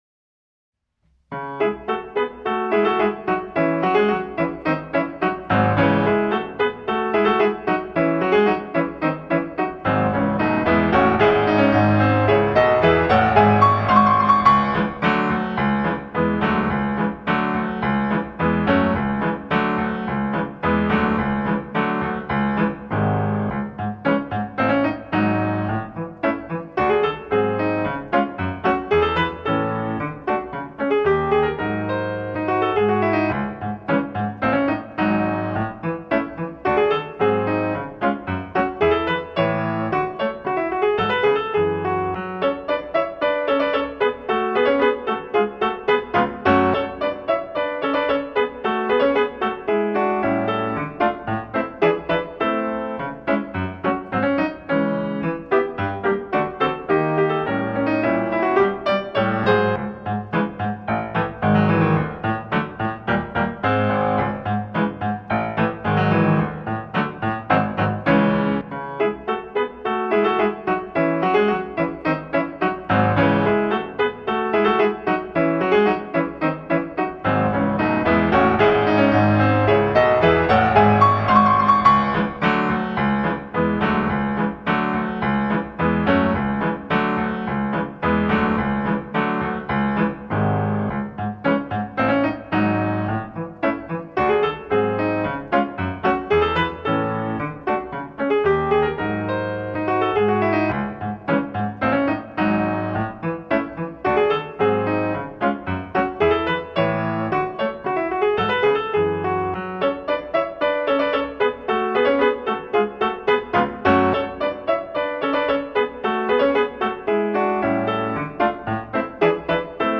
【Instrumental】 mp3 DL ♪